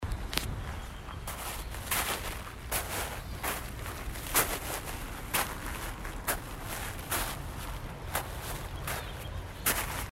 Walk_on_the_Stone.mp3